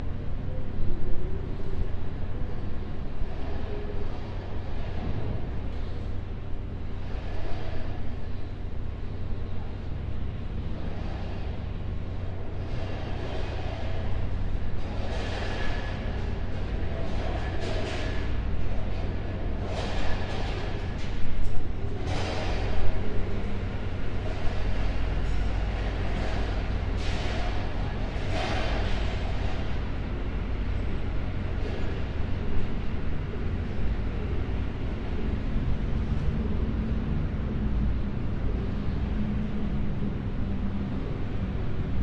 电梯 " 电梯口
描述：电梯开门。使用Zoom H4录制并使用Audacity编辑。
标签： 升降机 现场记录
声道立体声